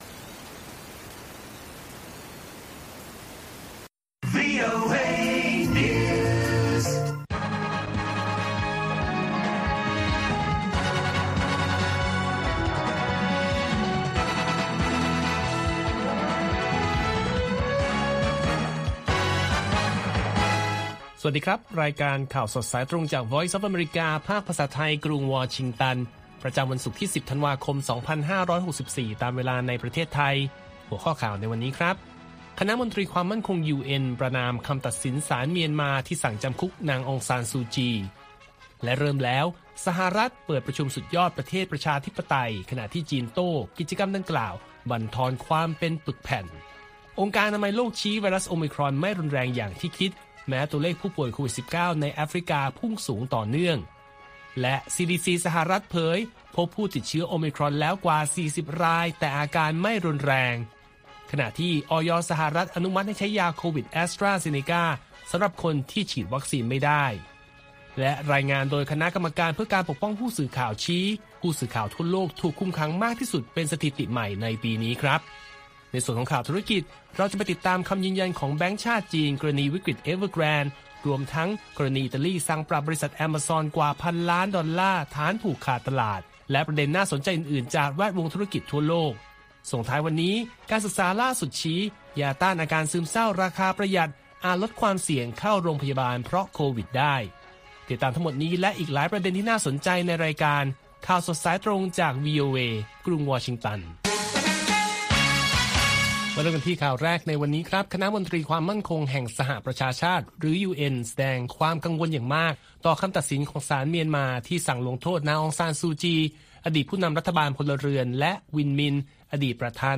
ข่าวสดสายตรงจากวีโอเอ ภาคภาษาไทย ประจำวันศุกร์ที่ 10 ธันวาคม 2564 ตามเวลาประเทศไทย